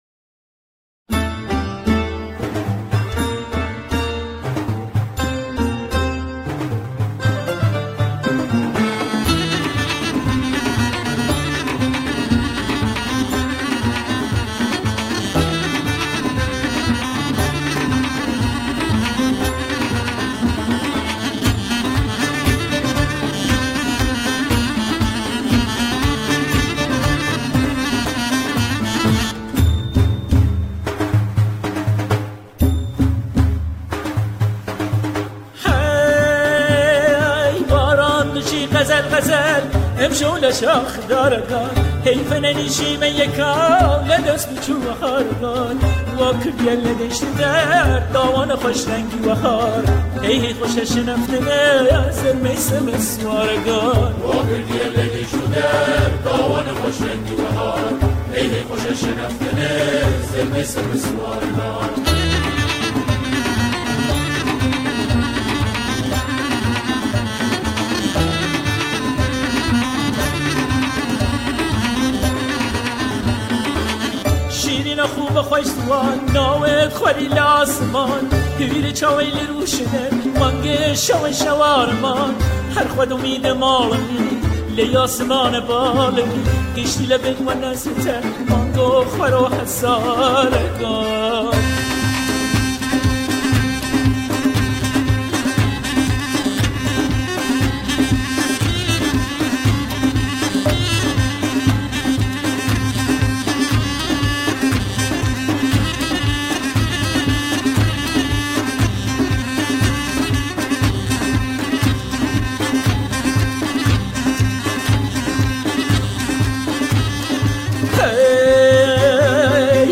گروه همخوان در این اثر، ابیاتی را به صورت مستقل اجرا می‌کند.